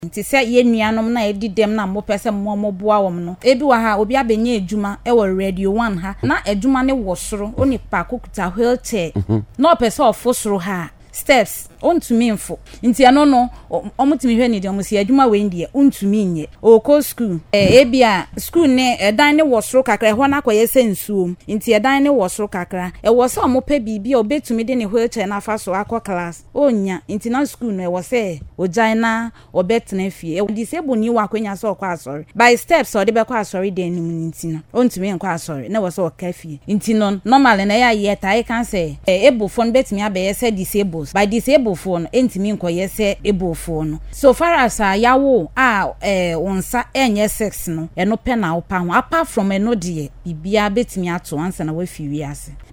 Speaking on Radio1’s morning show, she noted that many disabled individuals continue to face barriers in education, employment, and access to public facilities.